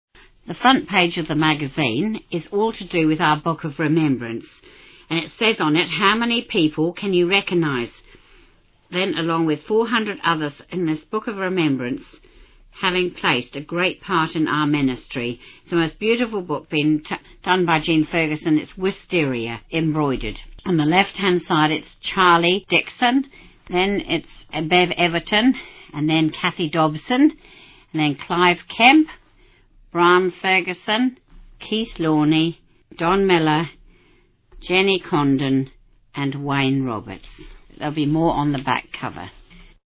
Genre: Speech..Released: 2016.